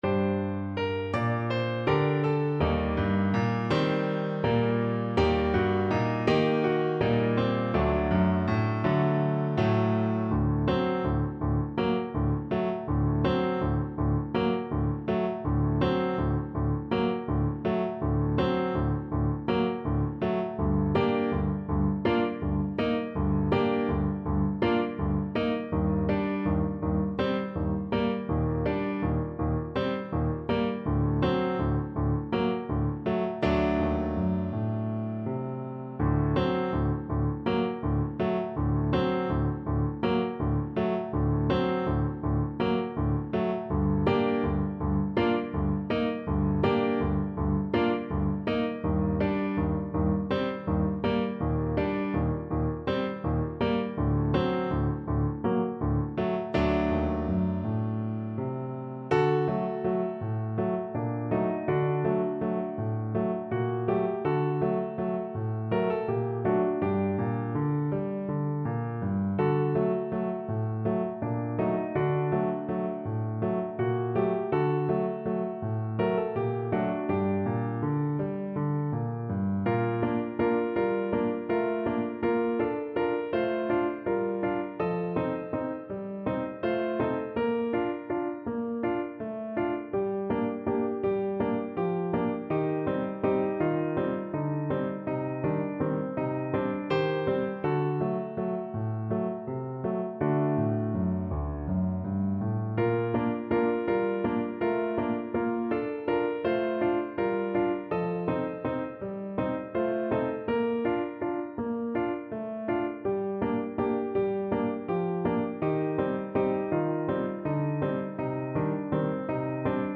Traditional Trad. Majko Majko Trumpet version
Play (or use space bar on your keyboard) Pause Music Playalong - Piano Accompaniment Playalong Band Accompaniment not yet available transpose reset tempo print settings full screen
G minor (Sounding Pitch) A minor (Trumpet in Bb) (View more G minor Music for Trumpet )
7/8 (View more 7/8 Music)
Moderato = 112
Traditional (View more Traditional Trumpet Music)
Eastern European for Trumpet
Serbian